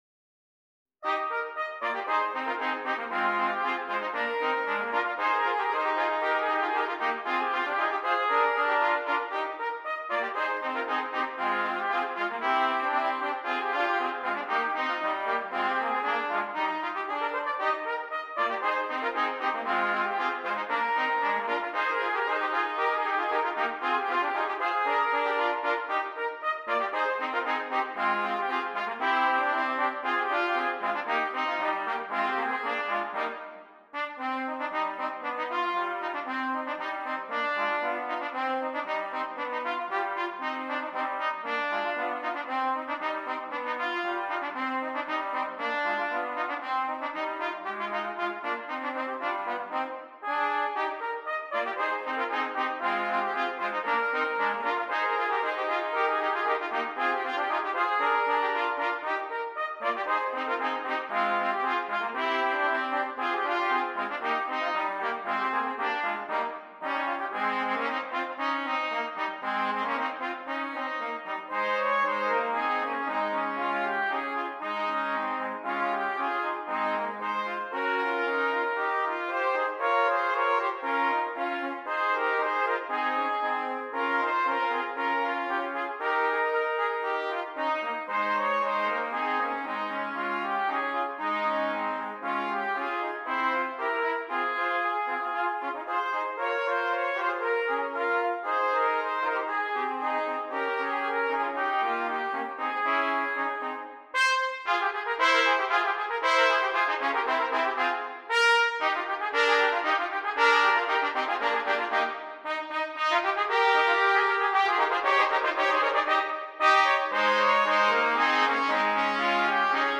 3 Trumpets